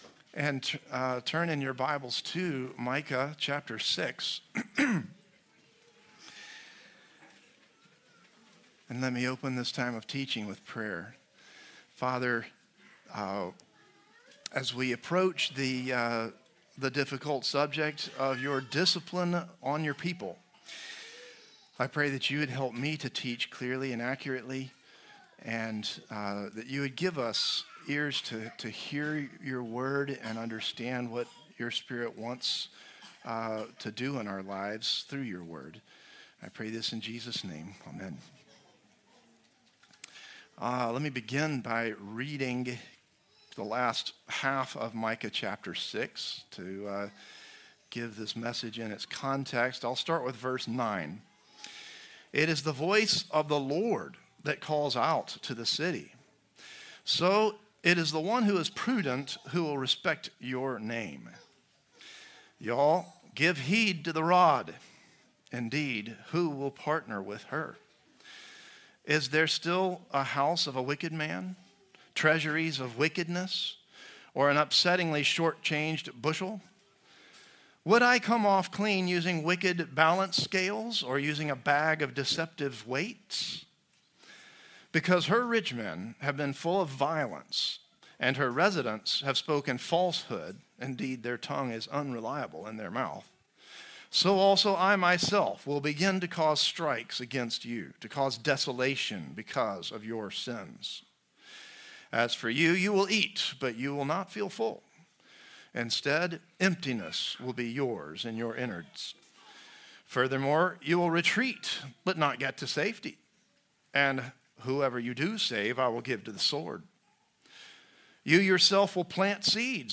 Christ the Redeemer Church | Sermon Categories Repentance